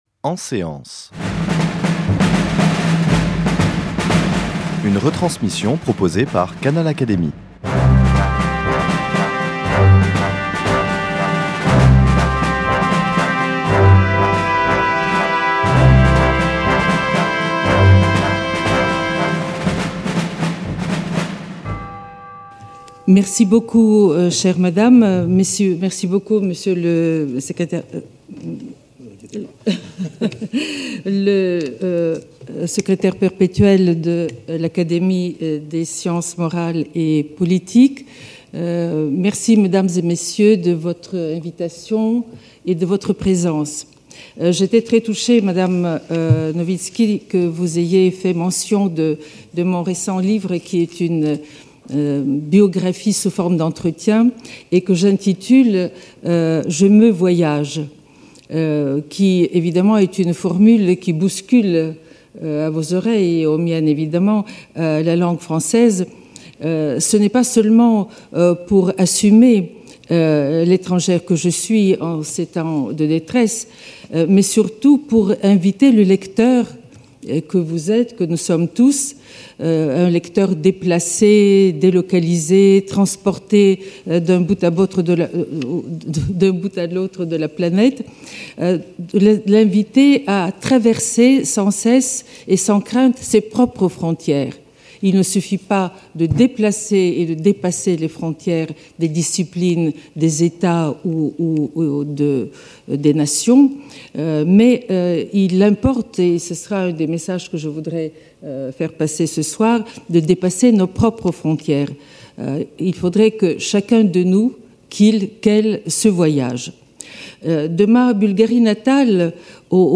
/ EN SÉANCE / Académie des sciences morales et politiques / La vie de l’esprit dans l’Europe du centre-est depuis 1945 / « La culture européenne existe-t-elle ?
» Conférence de Julia Kristeva, psychanalyste et philosophe, prononcée à l’Académie des sciences morales et politiques Lettres Arts Monde Publié le 15 mars 2017 Écouter (69 min.)